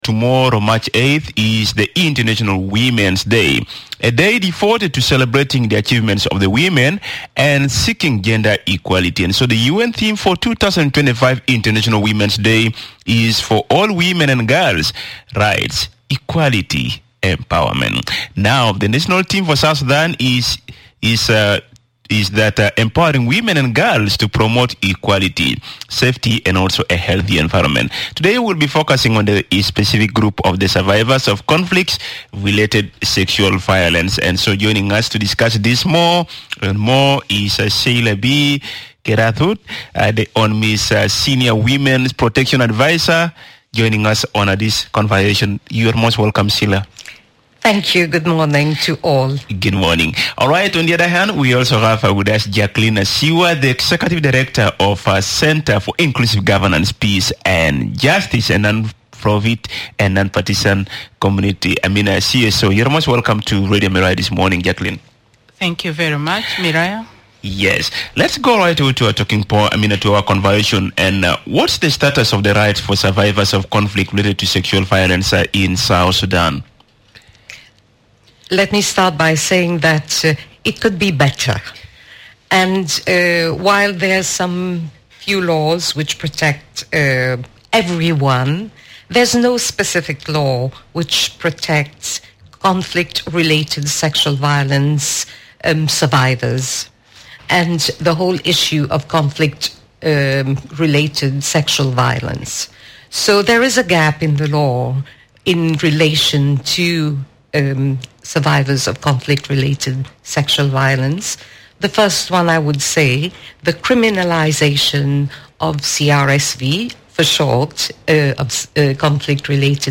MBS. International Women's Day in South Sudan: A Conversation on Survivors of Conflict-Related Sexual Violence